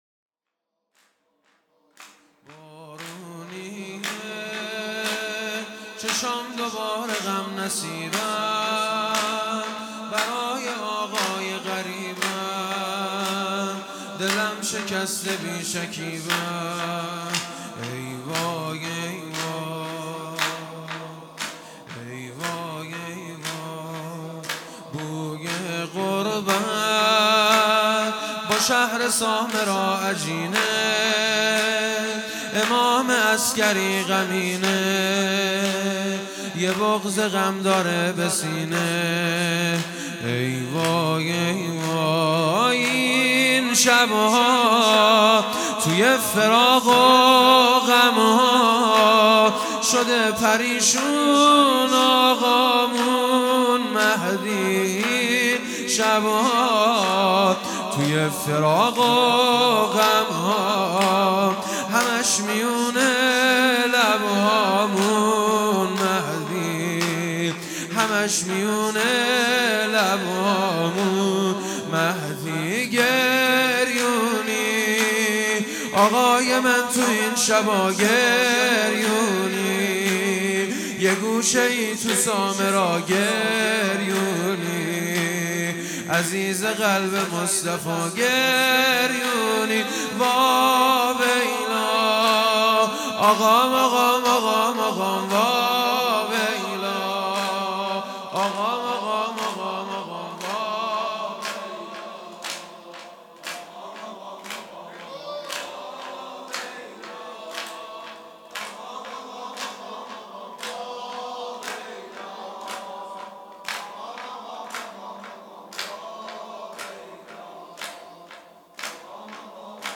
بارونیه چشام دوباره|شهادت امام هادی (ع) ۹۷